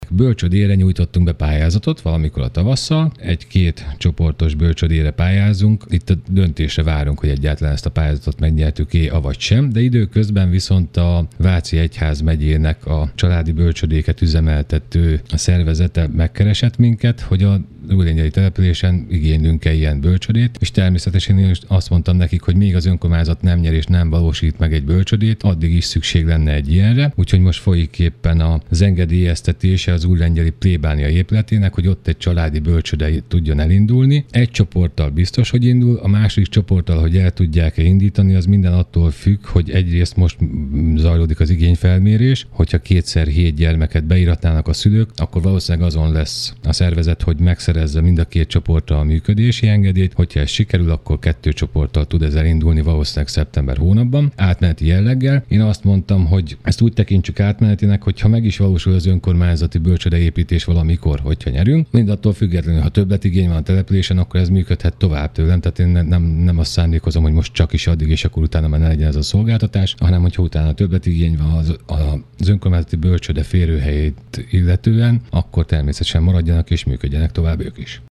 Újlengyelben szintén jelentkezett a Váci Egyházmegye, hogy bölcsődét alapítanának. Petrányi Tamás polgármester azt mondta, szívesen látják őket, miközben önkormányzati intézményre is pályáznak.